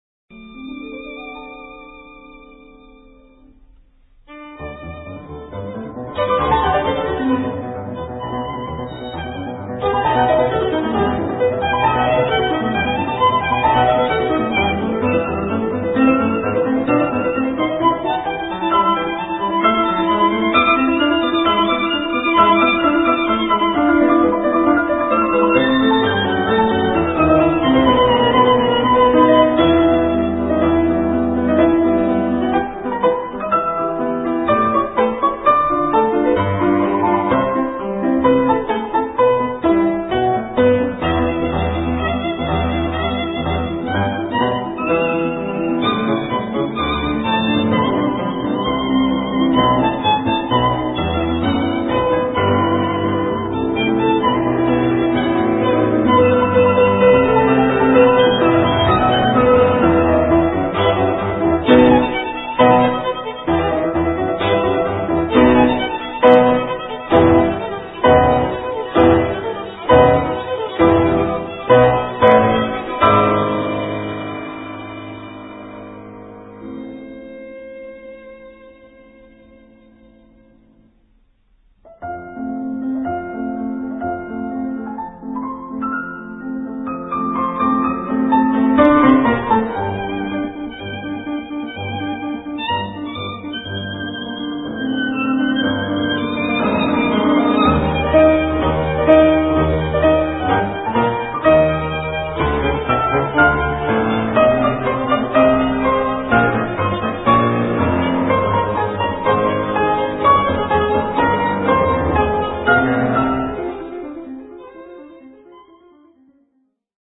«Страшная первая часть», adagio sostenuto (presto), как и вся соната, многократным (блестящим!) повторением одних и тех же музыкальных фраз вводит слушателя в состояние мечущейся мысли: когда одна и та же мысль мечется в мозгу, словно белка в колесе, не находя выхода.